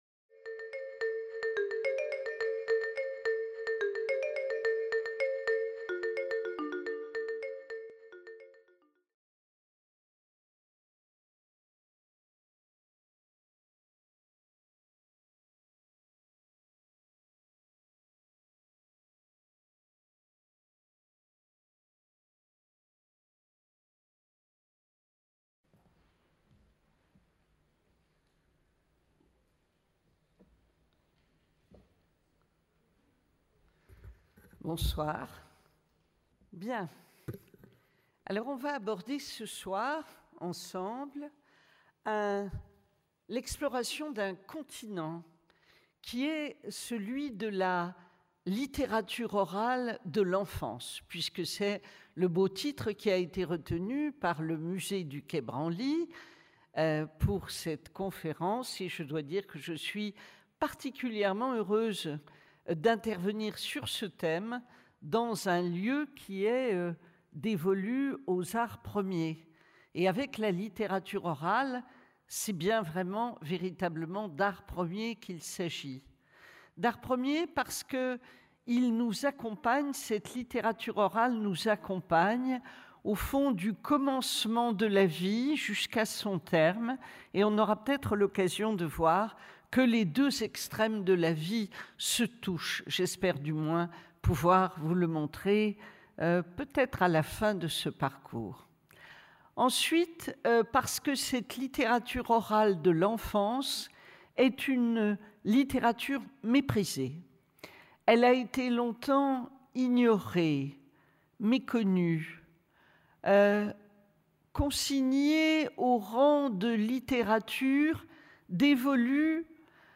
Conférence de l’Université populaire du quai Branly (UPQB), donnée le 30 novembre 2016 Cycle : L'ENFANCE Le cycle L'Enfance interroge les différentes facettes de l'enfance et ses étapes, à travers des prismes aussi variés que la culture enfantine, l'adoption ou l'éducation. LE FOLKLORE ORAL DE L'ENFANT Devinettes, proverbes, formulettes, comptines participent de ce que l’on appelle improprement les "petits genres" de la littérature orale. Or ces rimes et jeux de l’enfance dessinent un immense continent poétique, mystérieux et mal connu.